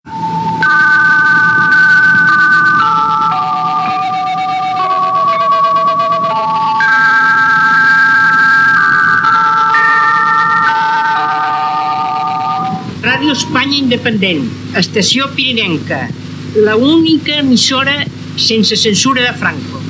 Sintonia de l'emissora i identificació en català